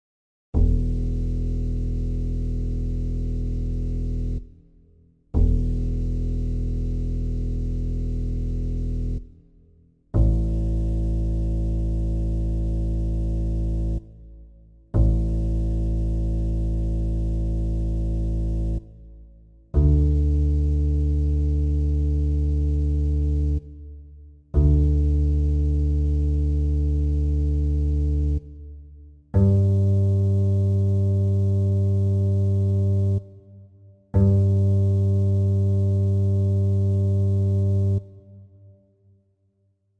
basstuneup.mp3